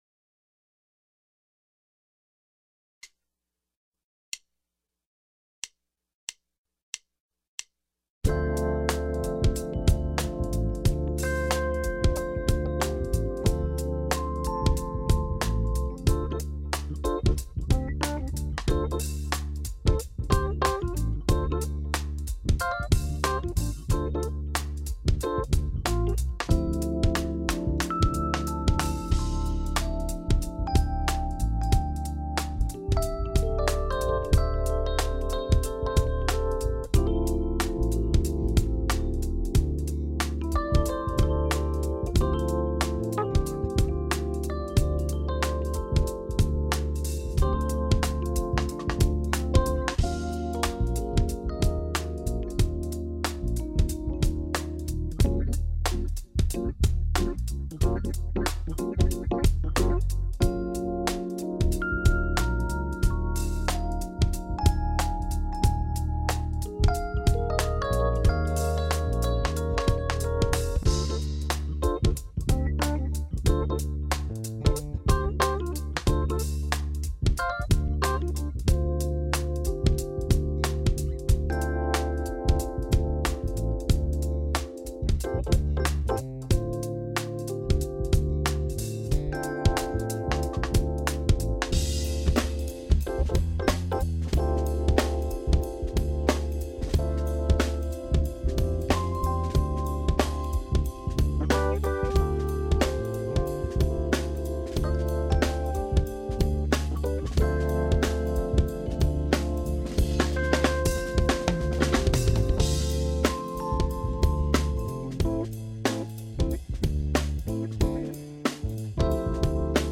Trade 4 bars with me, using two dorian modes.
MP3 Backing Track